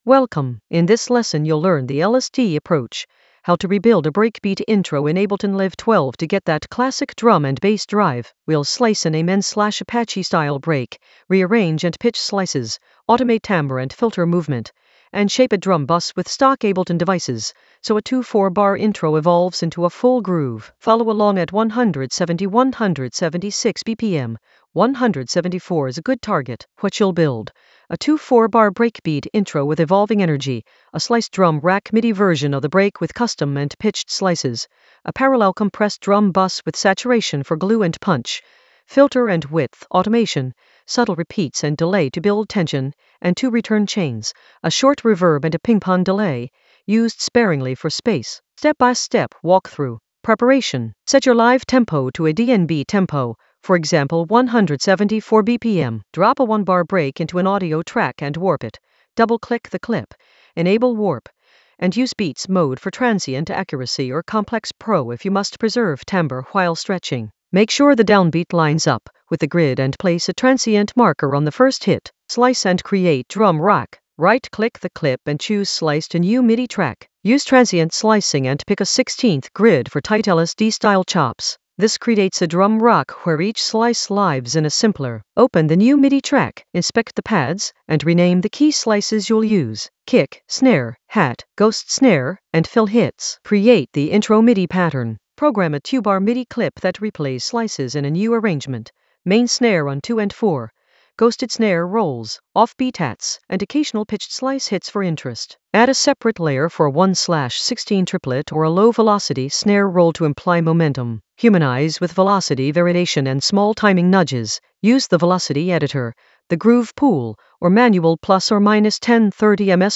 An AI-generated intermediate Ableton lesson focused on Ellis Dee approach: rebuild a breakbeat intro in Ableton Live 12 for classic drum and bass drive in the Drums area of drum and bass production.
Narrated lesson audio
The voice track includes the tutorial plus extra teacher commentary.